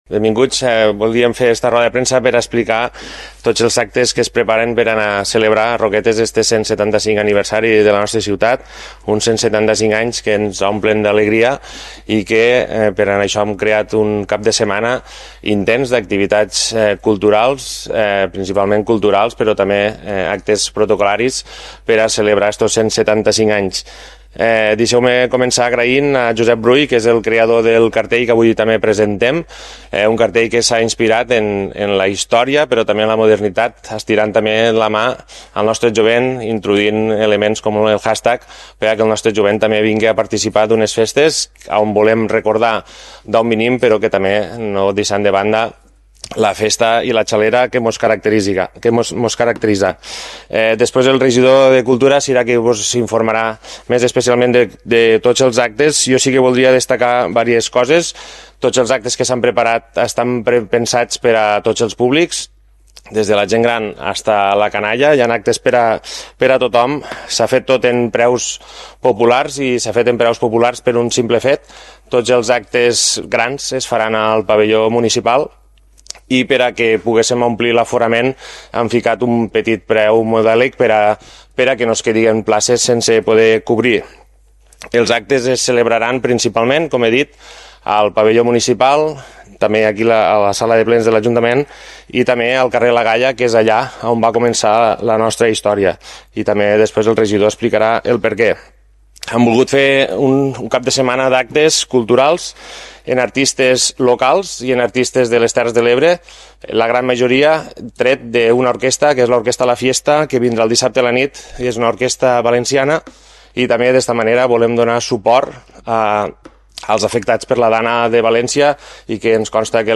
L’alcalde de Roquetes, Ivan Garcia, i el regidor de Cultura i Festes del municipi, Sisco Ollé, han estat els encarregats de presentar aquest dimarts el cartell i el programa complet d’activitats, explicant tots els detalls de la commemoració de l’efemèride.
Roda-de-premsa-de-la-presentacio-dels-actes-del-175e-aniversari-Roquetes-web.mp3